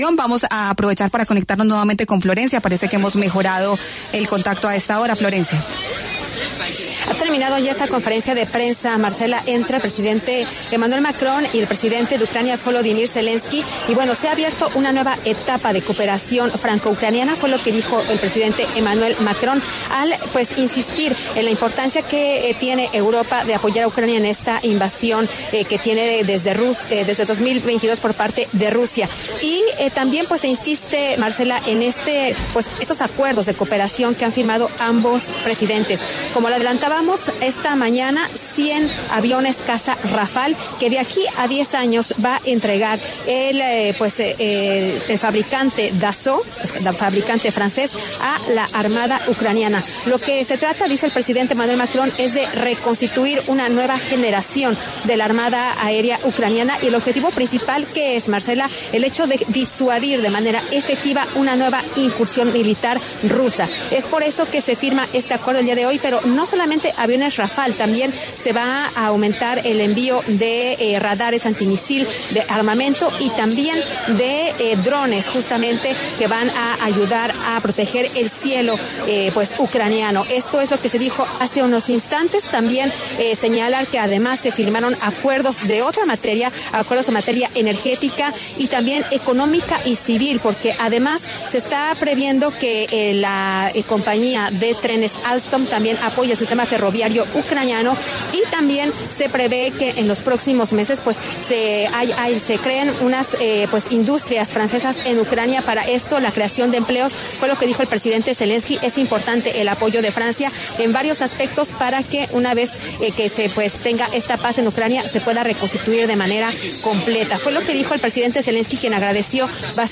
Jean-Noël Barrot, canciller de Francia, habló en La W sobre los acuerdos a los que llegaron su país y Ucrania tras reunión de ambos jefes de Estado.